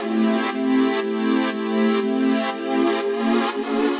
Index of /musicradar/french-house-chillout-samples/120bpm
FHC_Pad C_120-A.wav